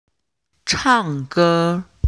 「e」が入った単語を声調別でリストアップしたので、音声を聞いてネイティブの発音をマネしてみて下さい。
「唱歌 chàng gē」の発音